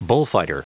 Prononciation du mot bullfighter en anglais (fichier audio)
Prononciation du mot : bullfighter